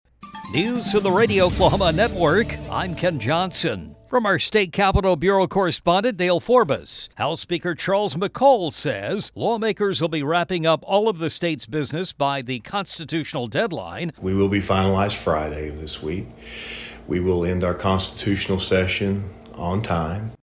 CLICK HERE to listen to comments from Charles McCall regarding Oklahoma's constitutional session.